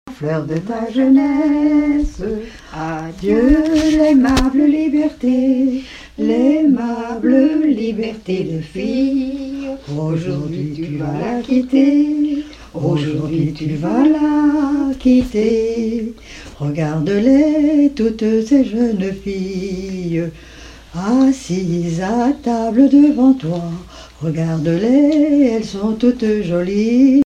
circonstance : fiançaille, noce
Genre strophique
Chansons en français
Pièce musicale inédite